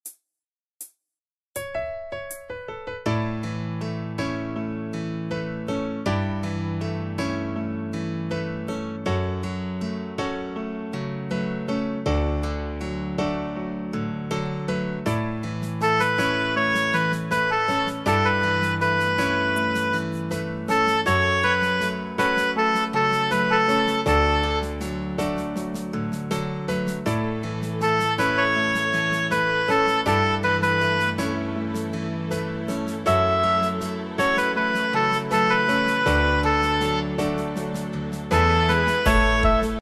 Tempo: 80 BPM.
MP3 with melody DEMO 30s (0.5 MB)zdarma